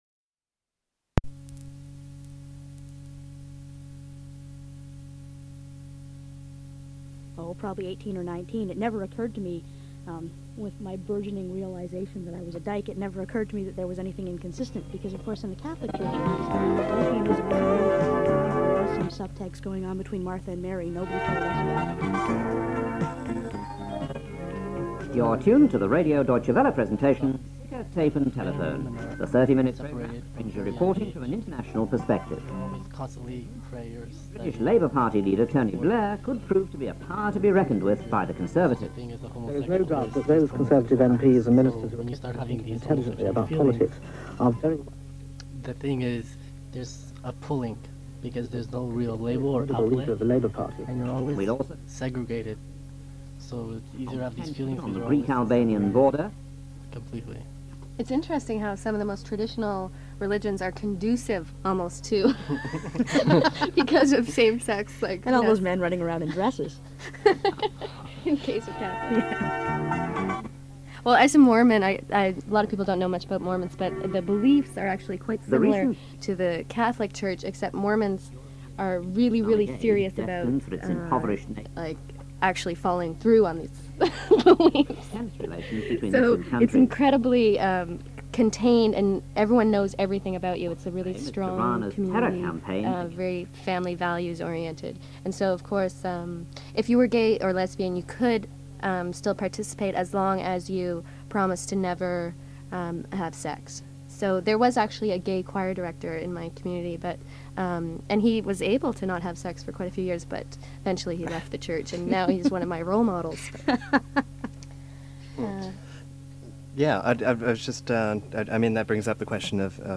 Interview was cut from the tape recording.
The Dykes on Mykes radio show was established in 1987.
Note: The beginning of the tape recording was overwritten with a separate radio program. First three minutes of the interview are broken up.